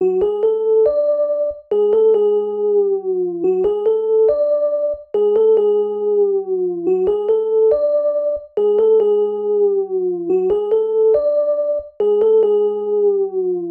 Tag: 130 bpm Trap Loops Bells Loops 1.24 MB wav Key : Unknown FL Studio